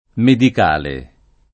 medik#le] agg.